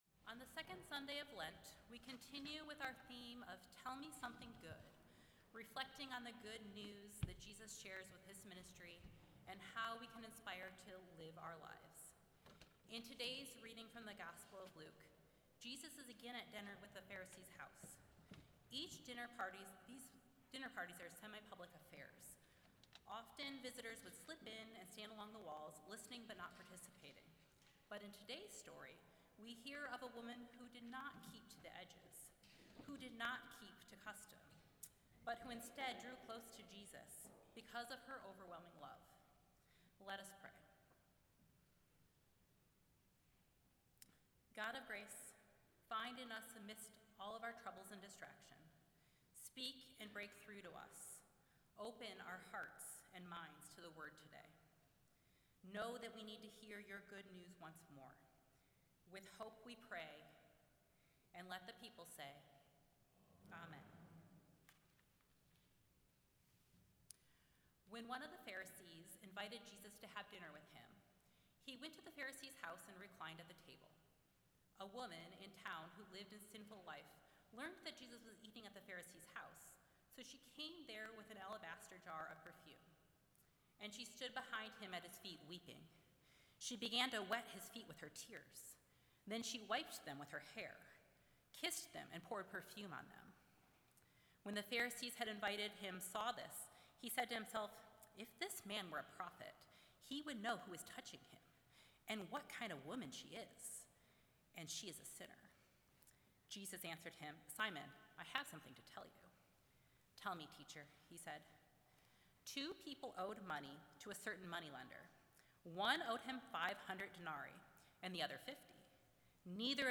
Sunday-Sermon-March-1-2026.mp3